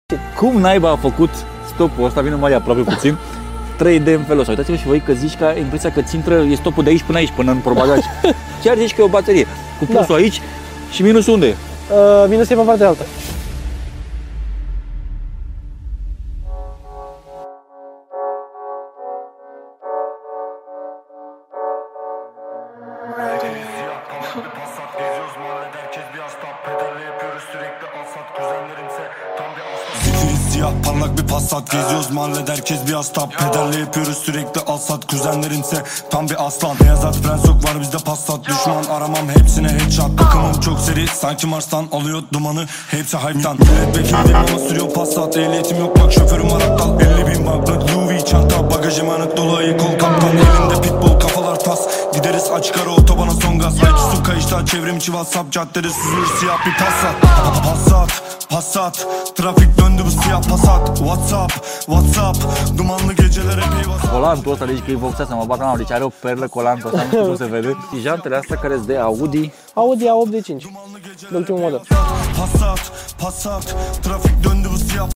Passat B5.5 (2.5 Tdi) sound effects free download